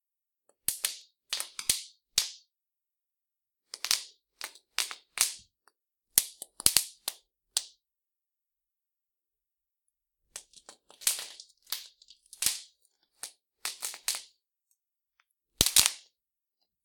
Plástico de burbujas
Pequeñas explosiones de un plástico de burbujas.